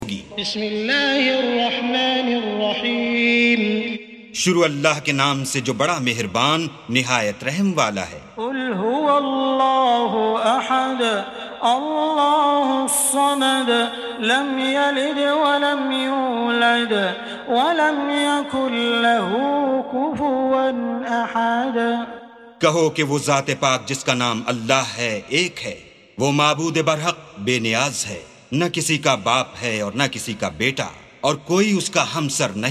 سُورَةُ الإِخۡلَاصِ بصوت الشيخ السديس والشريم مترجم إلى الاردو